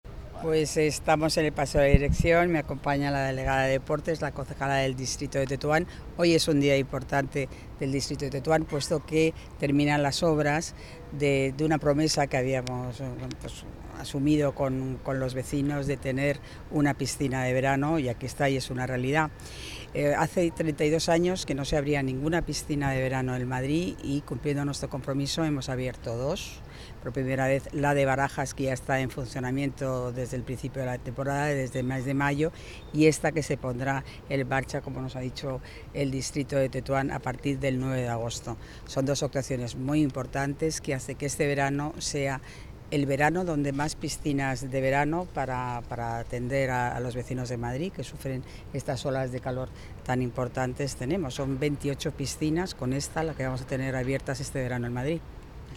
Nueva ventana:Palabras de la delegada de Obras y Equipamientos
(AUDIO) Declaraciones García Romero sobre nieva piscina de Tetuán.mp3